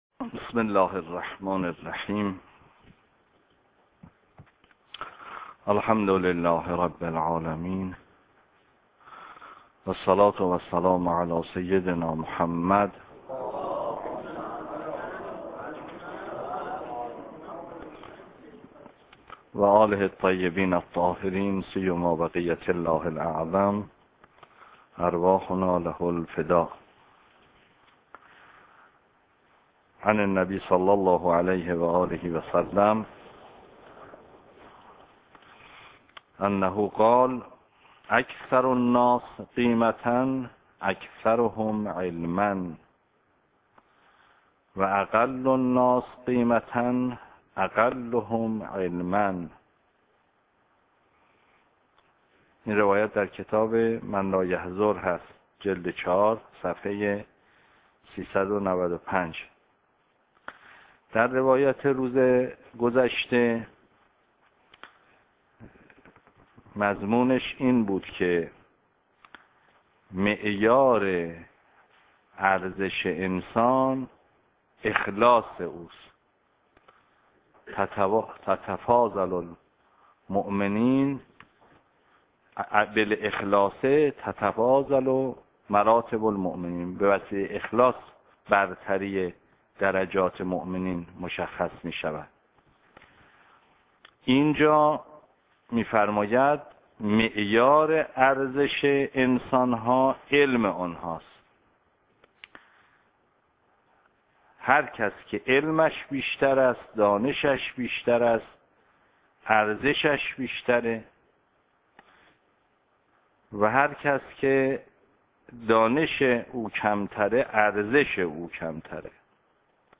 درس خارج فقه استاد محمدی ری شهری - الصلاة الجمعة